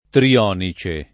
trionice [ tri- 0 ni © e ]